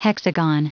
Prononciation du mot hexagon en anglais (fichier audio)
Prononciation du mot : hexagon